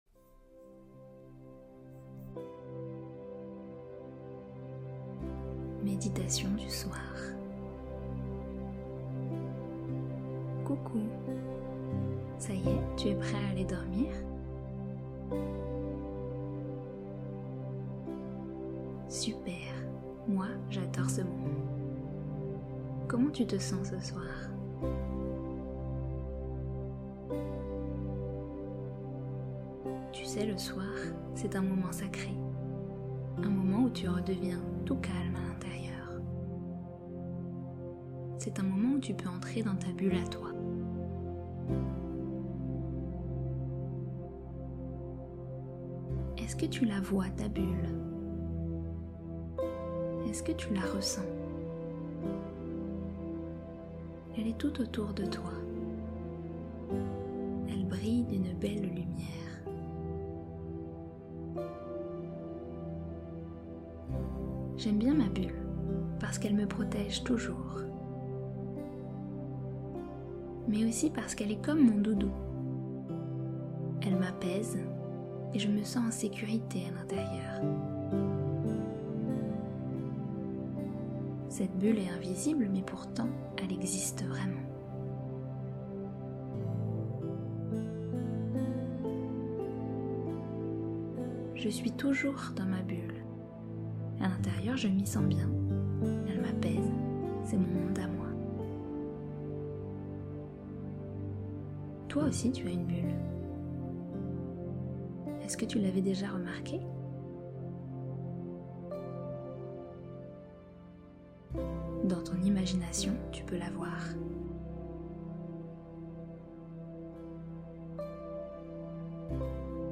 Dodo pour les schtroumpfs Méditation du soir pour les enfants (aide à l'endormissement) Play Episode Pause Episode Mute/Unmute Episode Rewind 10 Seconds 1x Fast Forward 10 seconds 00:00 / 7:00 Play in new window | Duration: 7:00